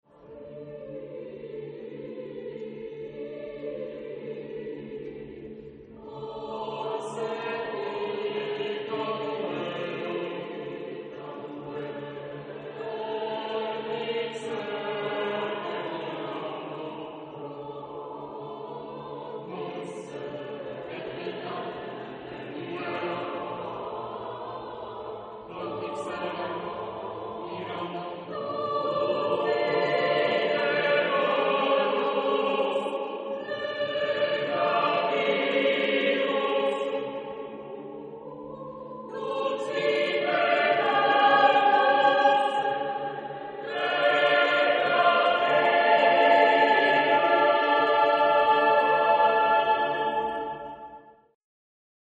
Genre-Style-Form: Vocal piece ; Sacred
Type of Choir: SSSAAATTTBBB  (12 mixed voices )
Tonality: atonal